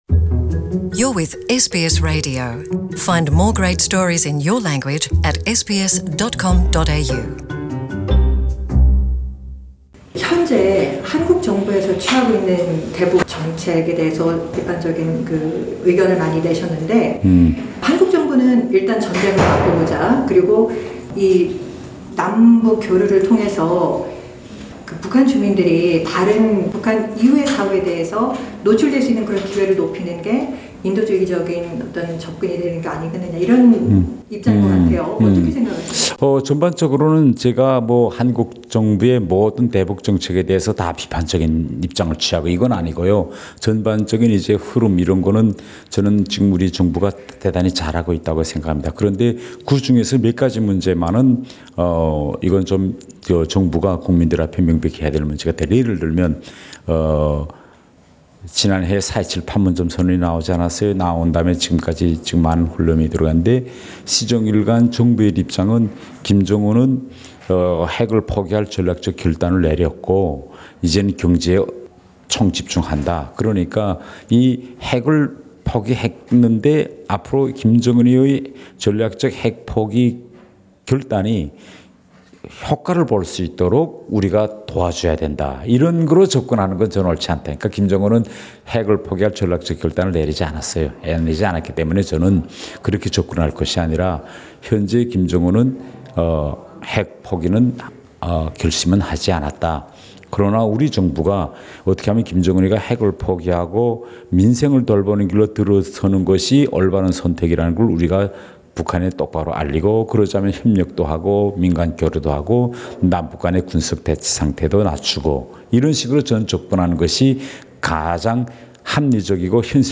최근 호주를 처음 방문한 태영호 전 영국주재 북한대사관 공사는 SBS 한국어 프로그램과의 단독 인터뷰에서 김정은 위원장의 답방의 중요성을 적극 부각시켰다. 즉, 더 많은 김위원장의 주변 인사들이 북한 밖의 사회를 경험할 수 있도록 해야한다는 것.